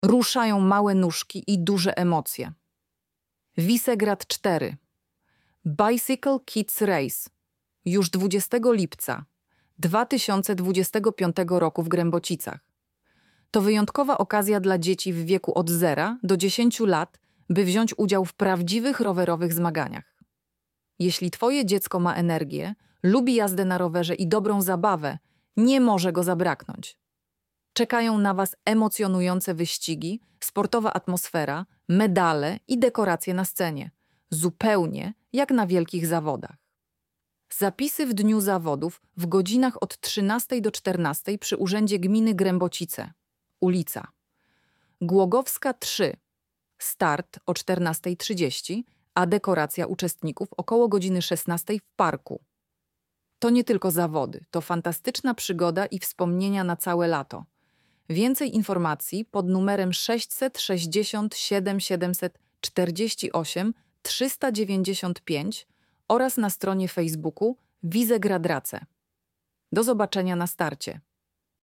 lektor-visegrad-race.mp3